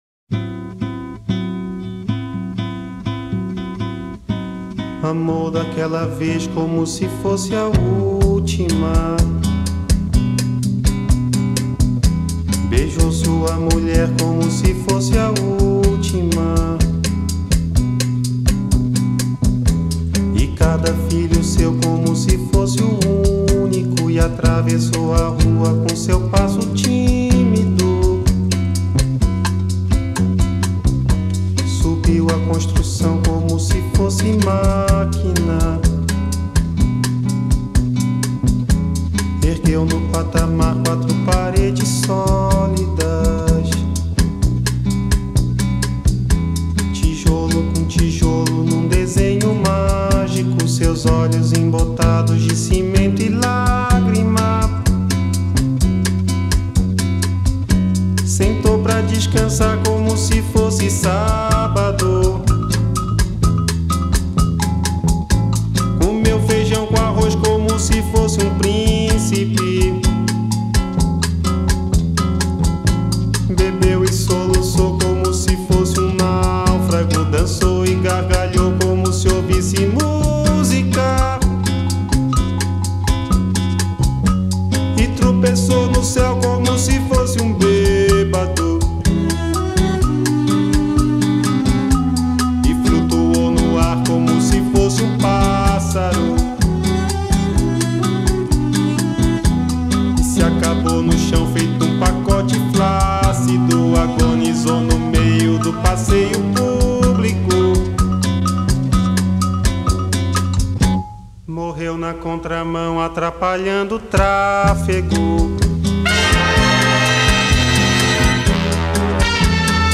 Género: MPB